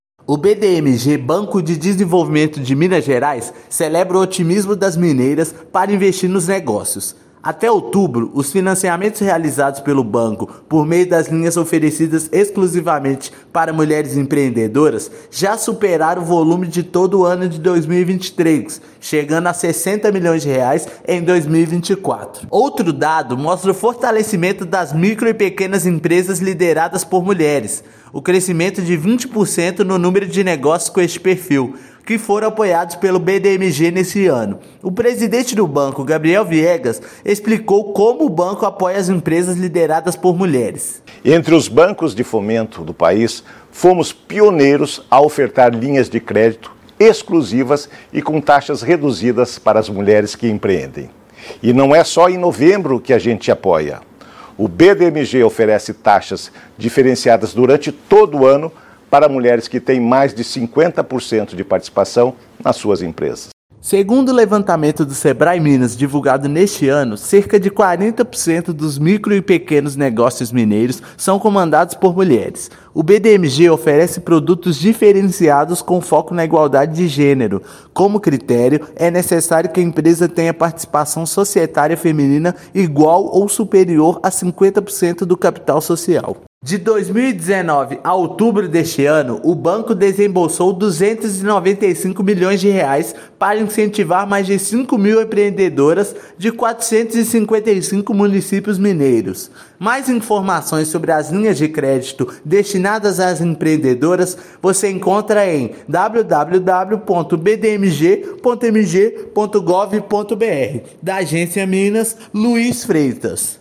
Banco de Desenvolvimento de Minas revela que os créditos liberados até outubro já superaram todo 2023. Ouça matéria de rádio.